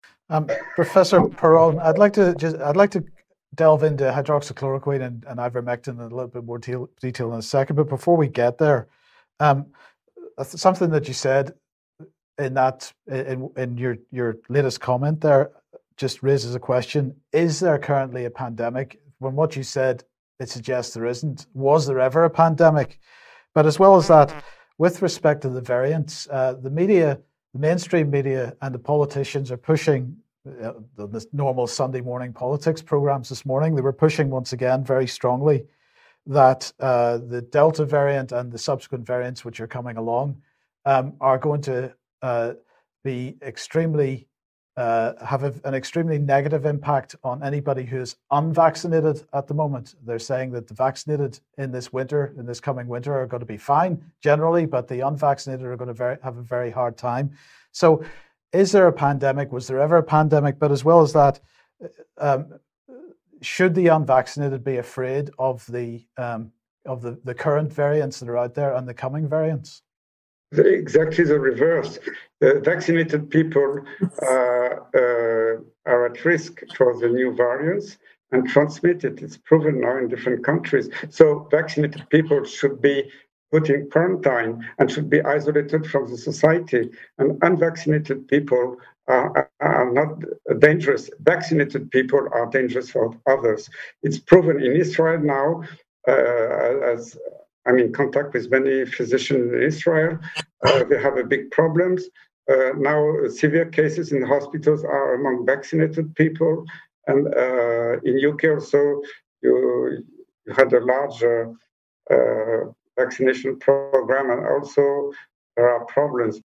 Der langjährige französische Experte und ehemalige Vizepräsident der Europäischen Beratenden Expertengruppe für Impfungen der Weltgesundheitsorganisation Professor Christian Perronne wurde in einem Interview mit UK Column gefragt, ob ungeimpfte Menschen Angst vor "Corona-Varianten" haben müssten.